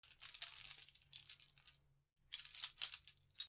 手錠の鍵を外す 05
/ K｜フォーリー(開閉) / K35 ｜鍵(カギ)